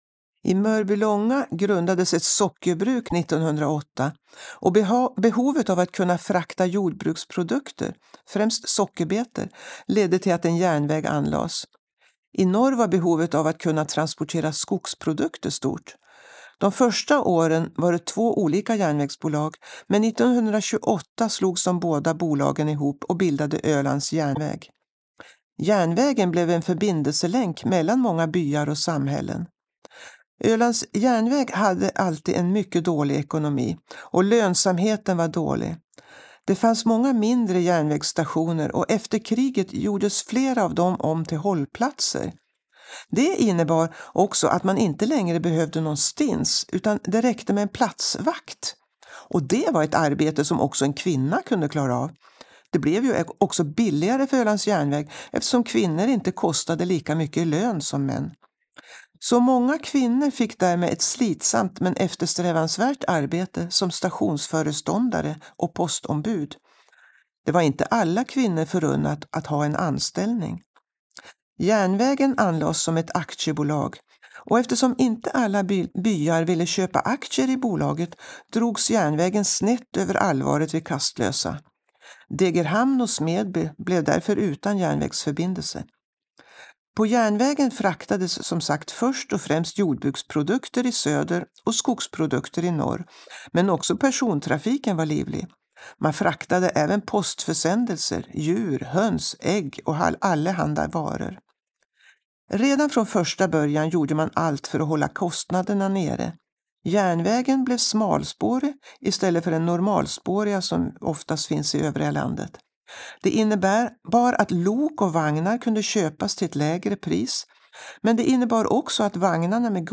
Audioguide med berättelse om järnvägen på Öland.